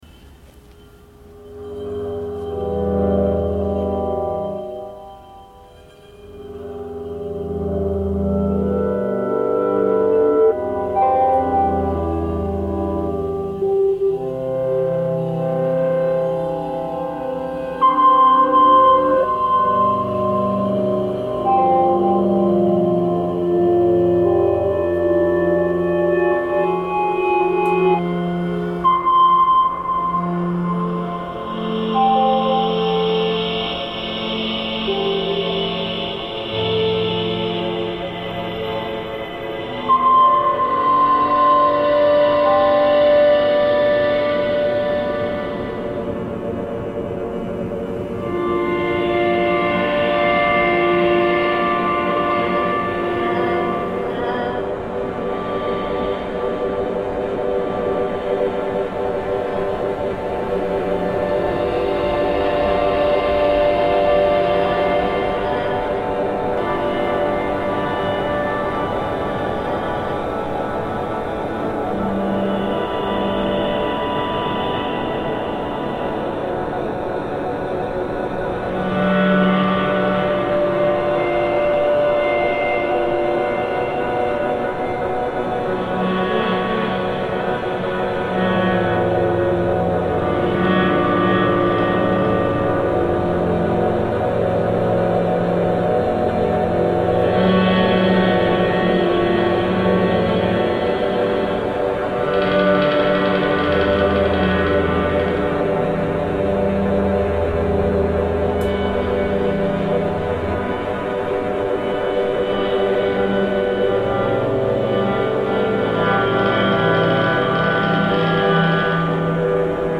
The original sample was very distinctive in both tempo and timbre (I believe it is the sound of Galician bagpipes).
Traditional bagpipes